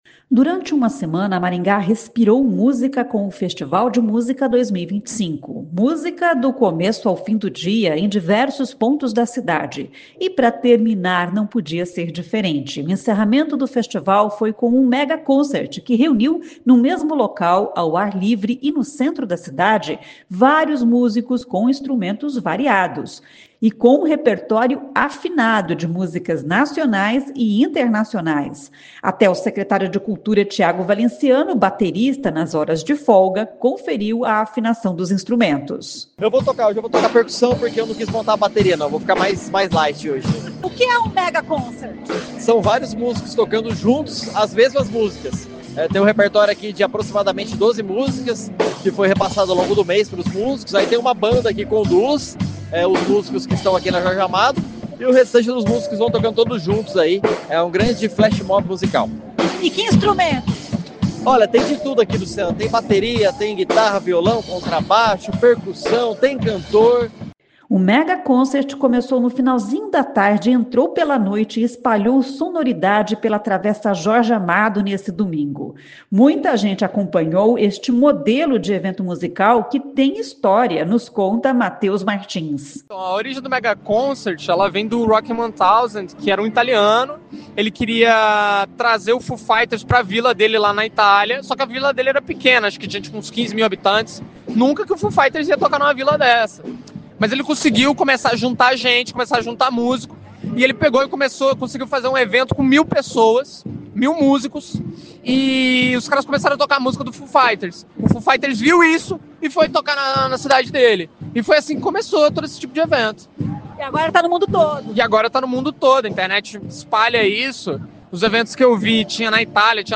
Até o secretário de Cultura, Tiago Valenciano, baterista nas horas de folga, conferiu a afinação dos instrumentos.